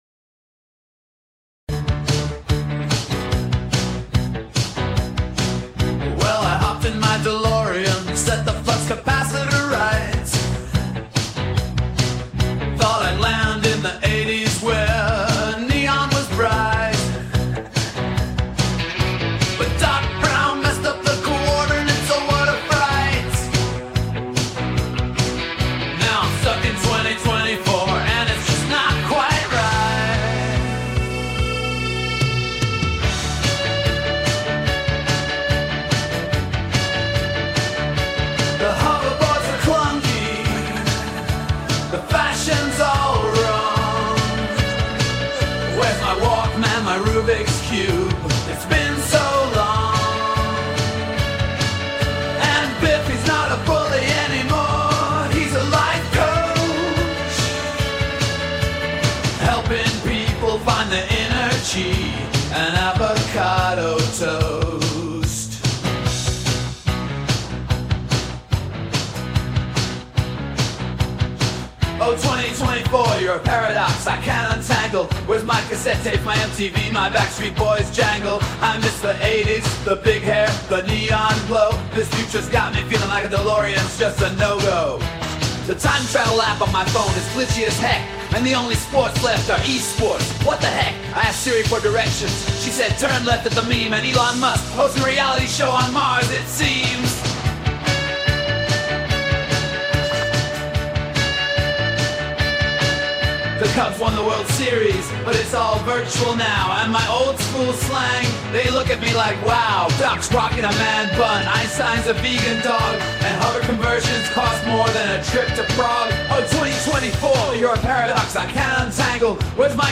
All AI generated